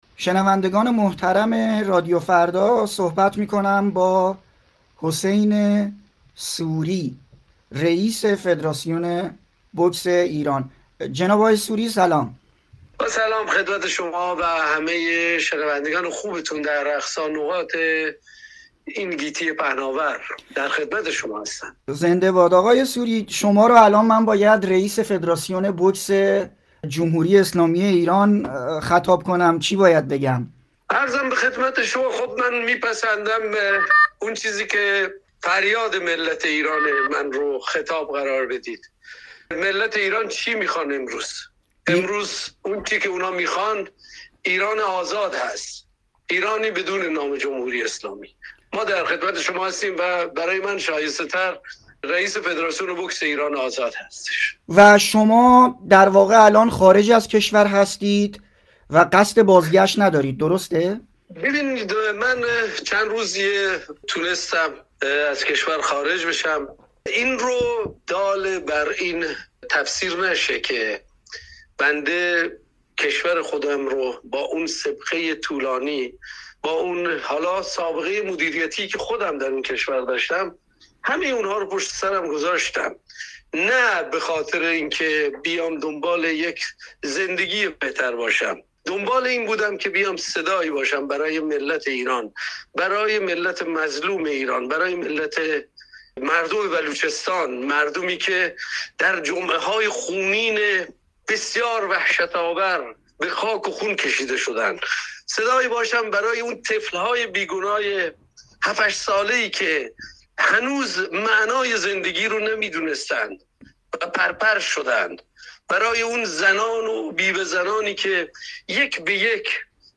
گفت‌وگوی رادیو فردا